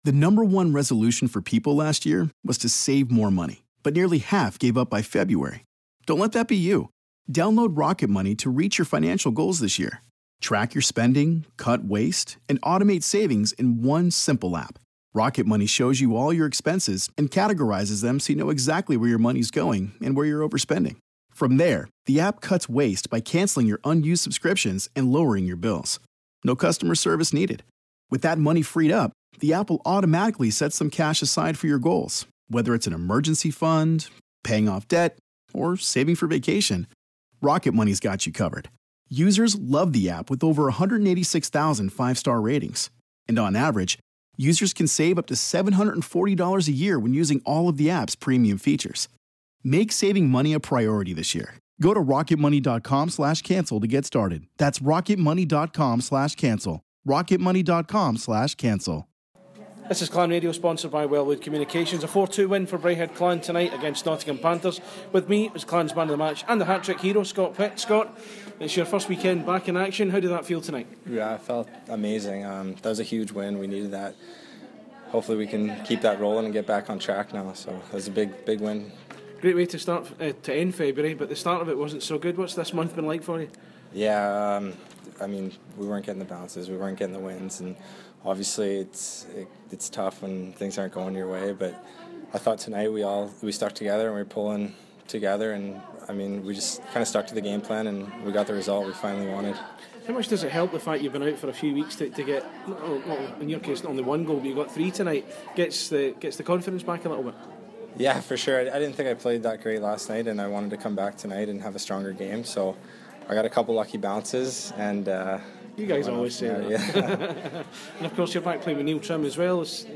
POST MATCH